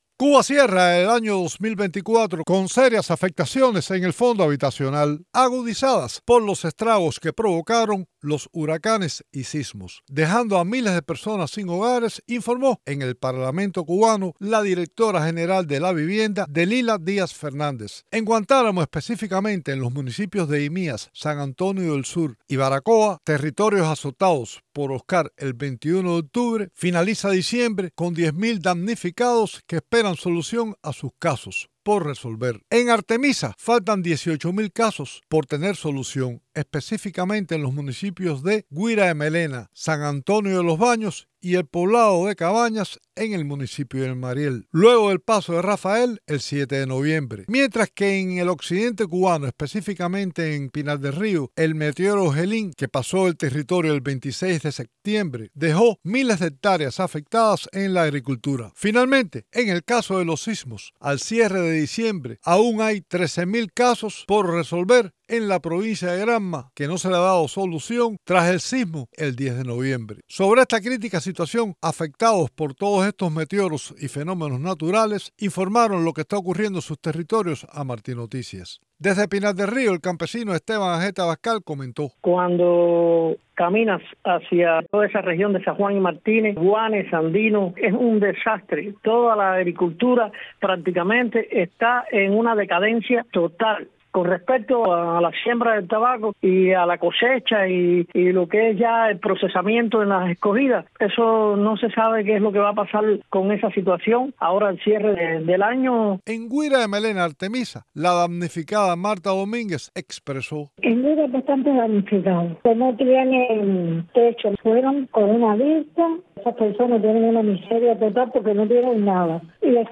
Los desastres naturales que afectaron a Cuba en 2024: Testimonios